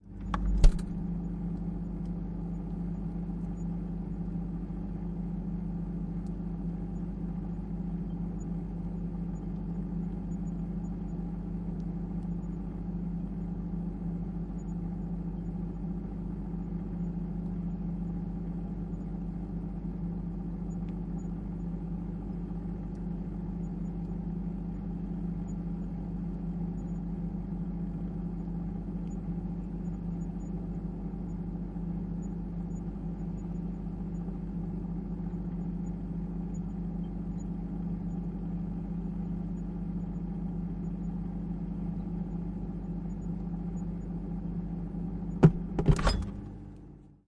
冰箱音
描述：录制冰箱音。使用Neumann KM185，Oktava MK012和Sound Devices 552录制。